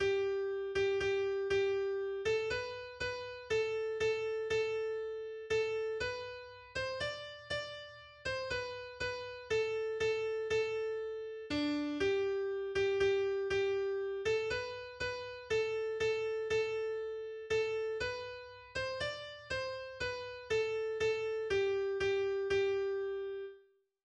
der zu einer alten umgeformaten Tanzweise gesungen wird.